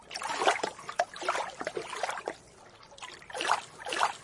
boat.mp3